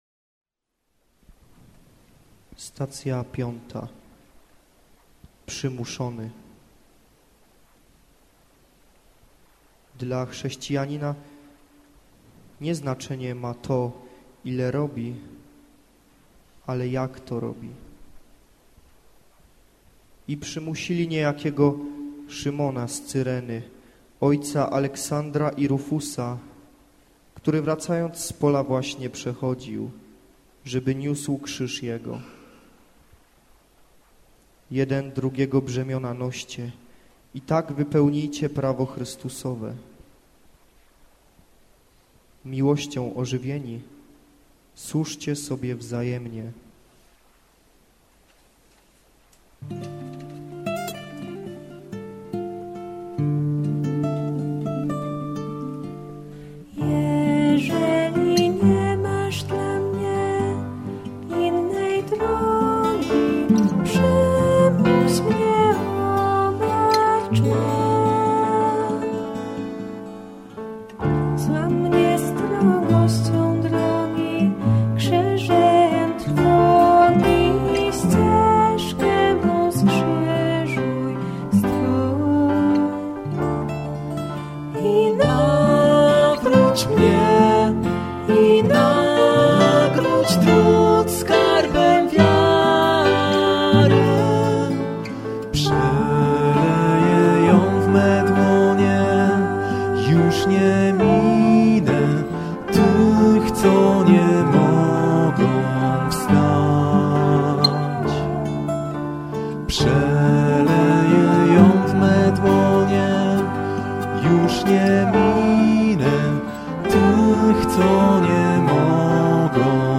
WokalGitaraKeyboard